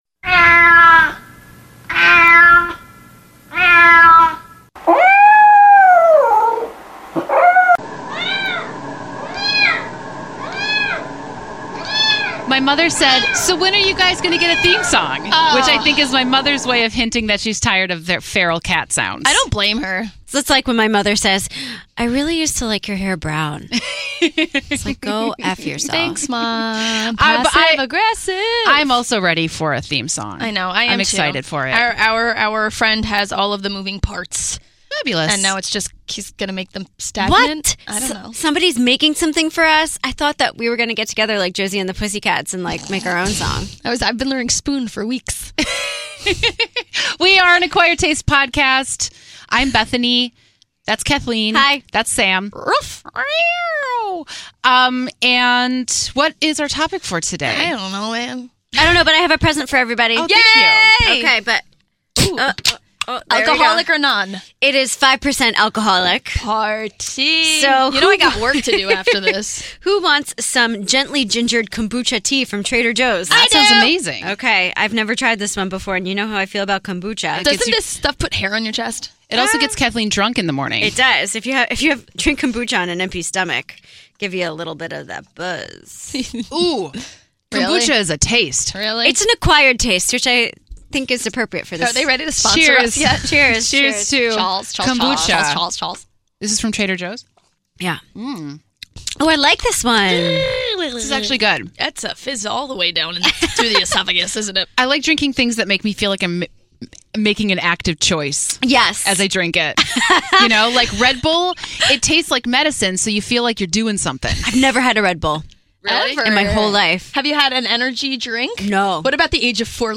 Note to our audiophile friends: the subtle buzzing you may hear in the background is a fidget spinner.